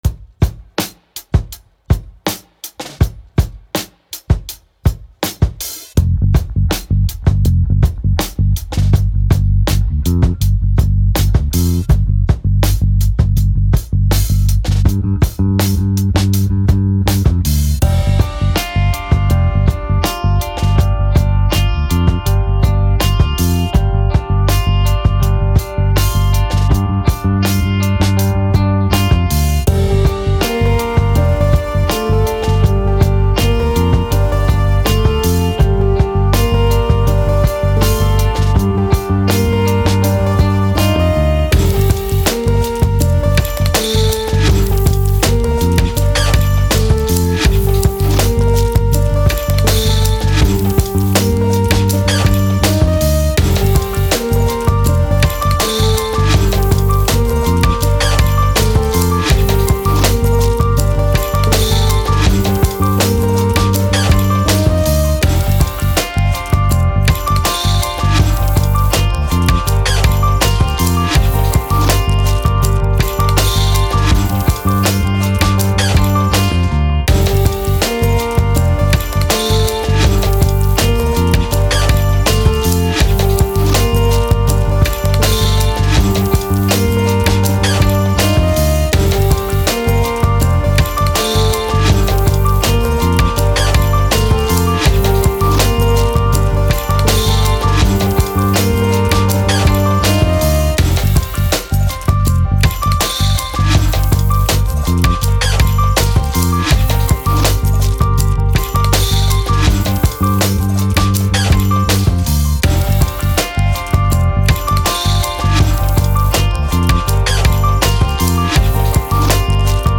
Trip Hop, Hip Hop, Ambient, Landscapes, Mystery